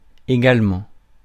Ääntäminen
IPA: [e.ɡal.mɑ̃]